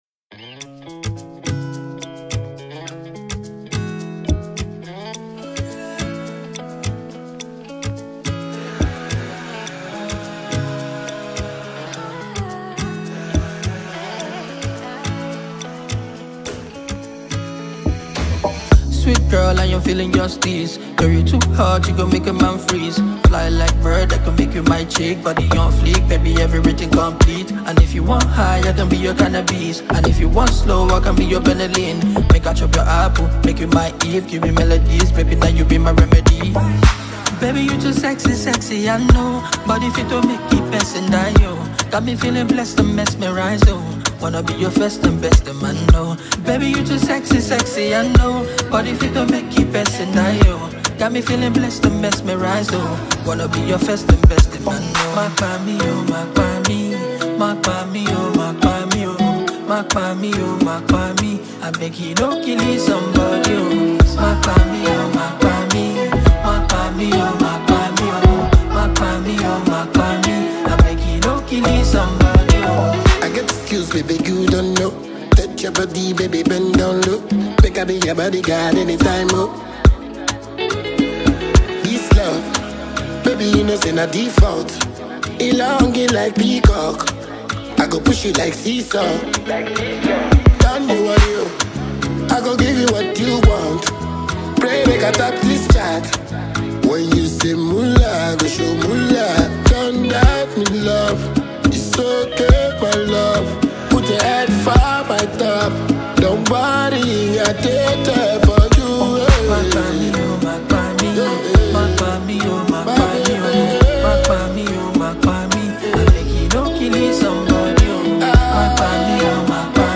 Singer, Rapper and Songwriter
Dancehall